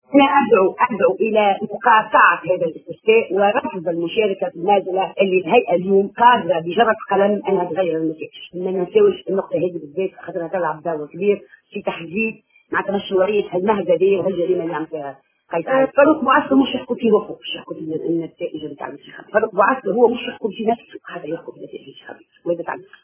Dans une déclaration faite à Tunisie Numérique, Abbou a ajouté que “J’appelle au boycott du référendum et à refuser de participer à cette campagne…Le président de l’Isie, Farouk Bouasker ne peut pas juger les résultats des élections…) a-t-elle dit.